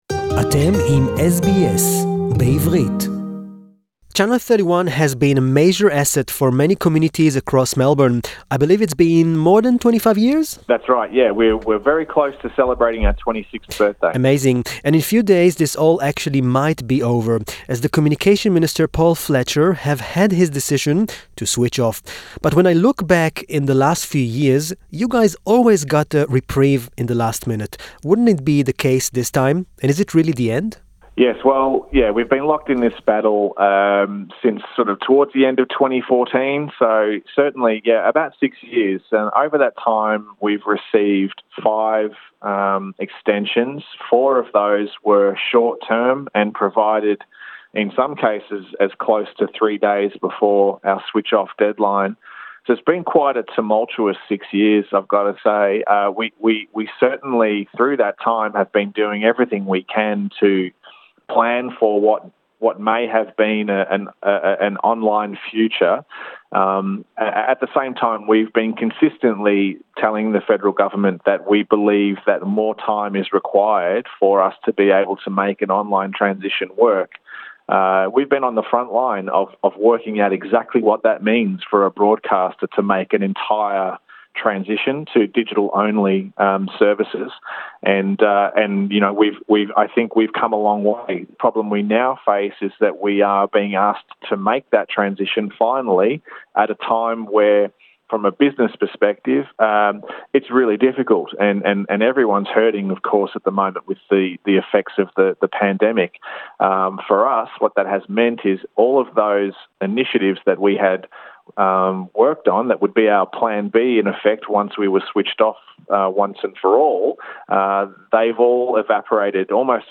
In a last minute interview